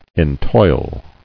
[en·toil]